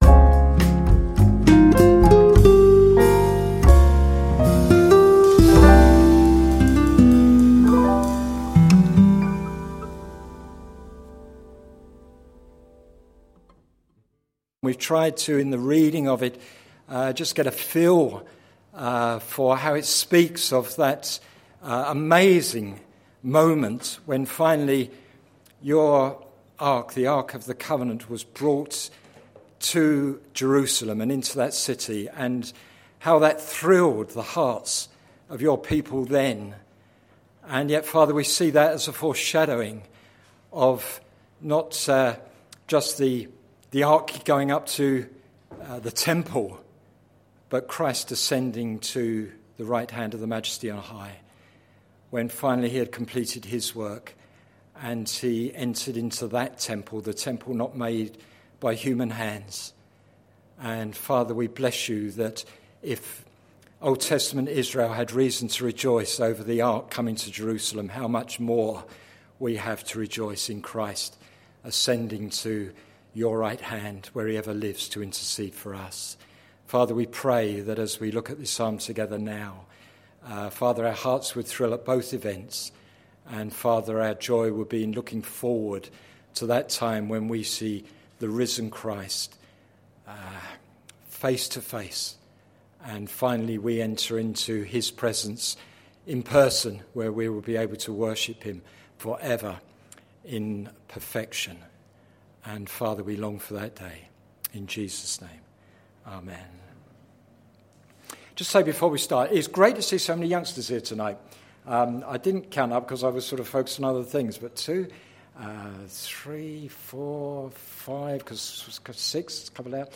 Sermon Series - Songs to live and songs to sing - plfc (Pound Lane Free Church, Isleham, Cambridgeshire)